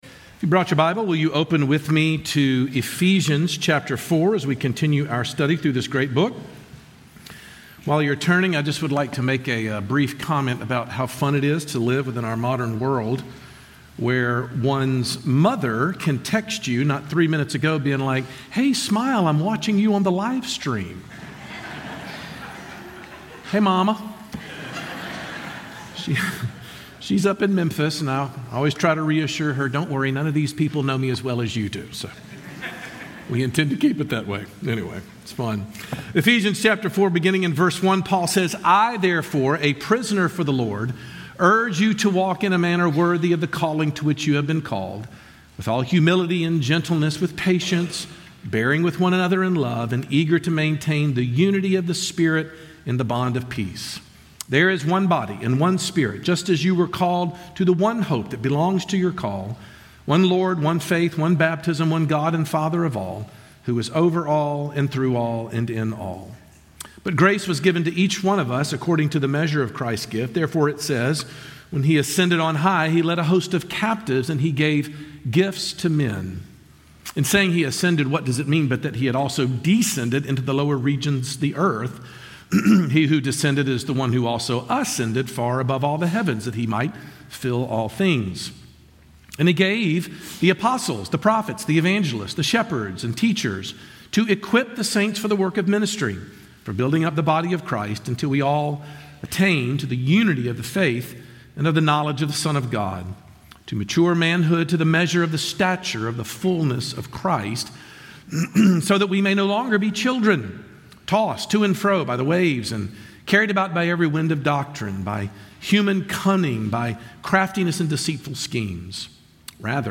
But Paul launches straight into the topic of Christian unity in Ephesians 4, making it a top priority for God's people to work towards if we hope to be a maturing church. Sermon